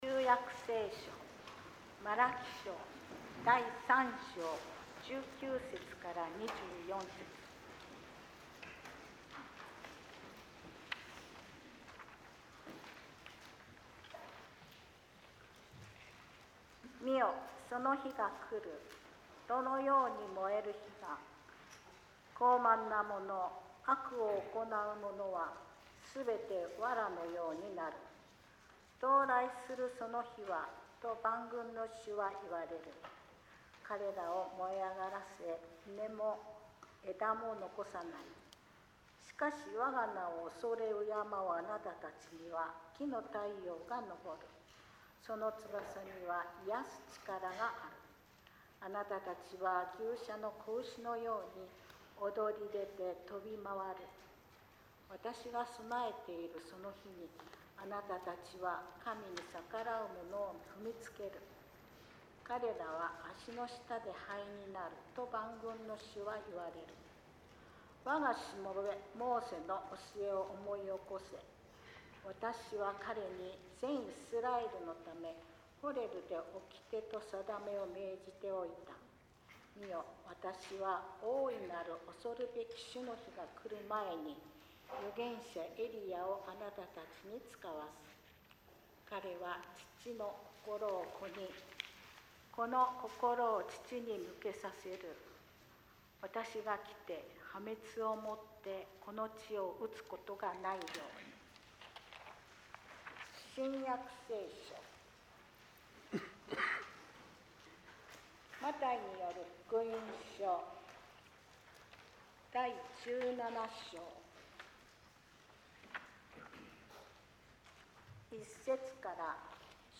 説教 「変貌の山を降りて」